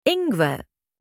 ginger-in-german-1.mp3